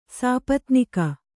♪ sāpatnika